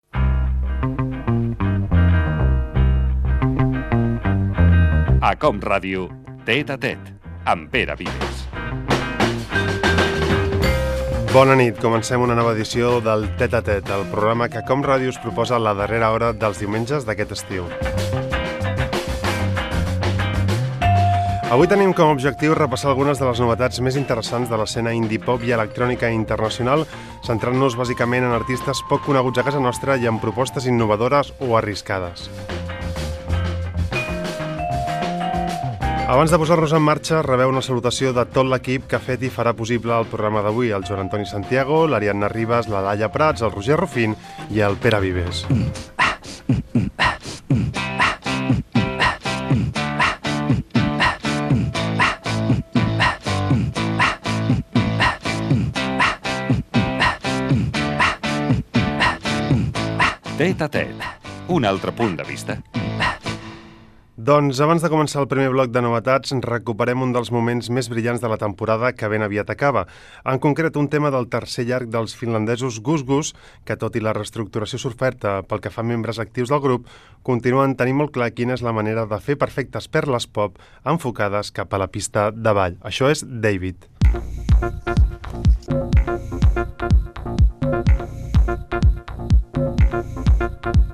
Identificació i presentació inicial del programa amb els noms de l'equip. Indicatiu i tema musical
Musical